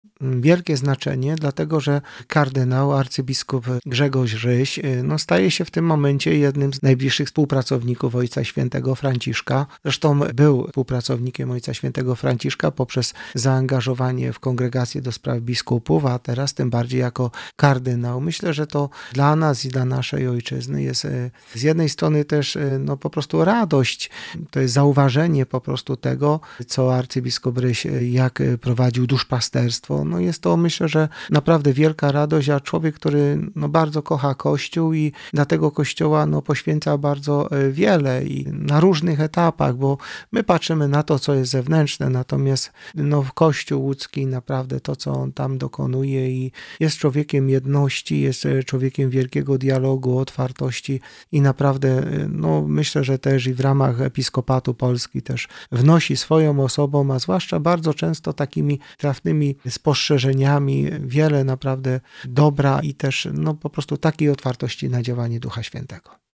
– Ten wybór ma ogromne znaczenie dla Kościoła. Kardynał Grzegorz Ryś to człowiek jedności i dialogu, który kocha Kościół – mówi dla naszej rozgłośni ojciec biskup Jacek Kiciński.